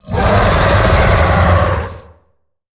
Sfx_creature_squidshark_idle_02.ogg